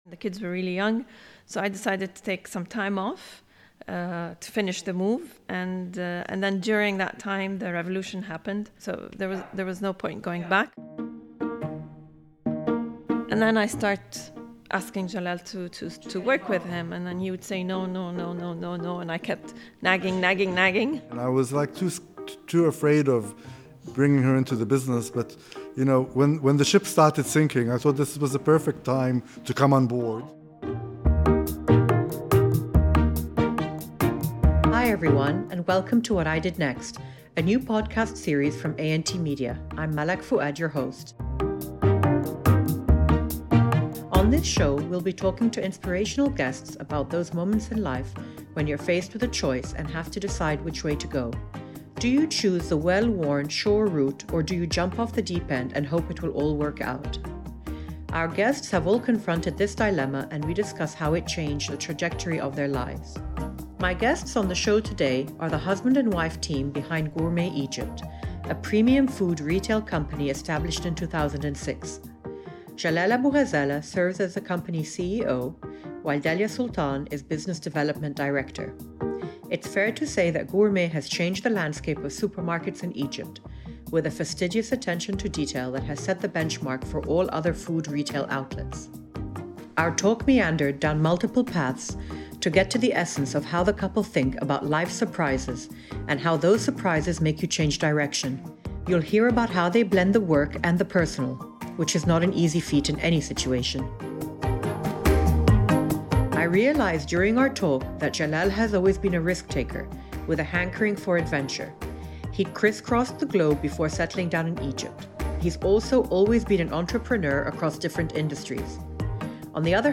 Join our conversation to hear about their pivot points — from the cute way they met to the challenges they encountered in the evolution of their business. The conversation blends the personal and professional and we meander down memory lane to key cross-roads along their path.